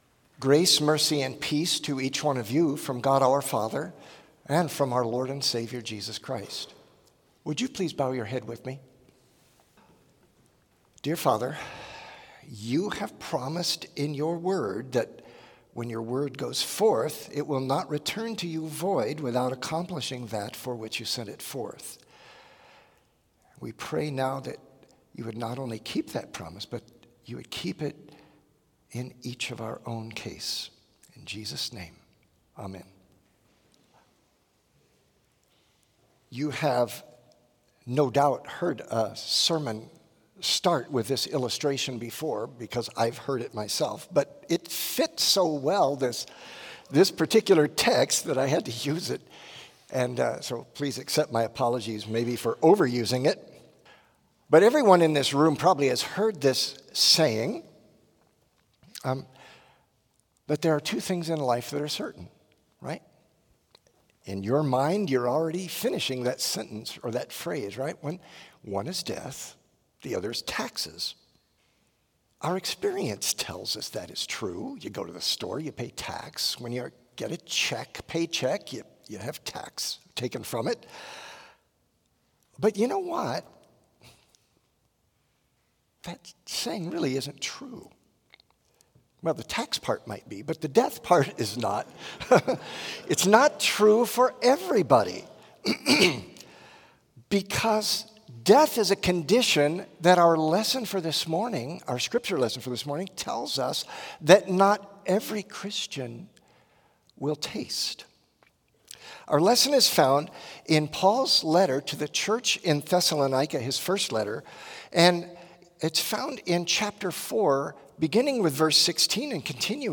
Complete service audio for Chapel - Friday, September 20, 2024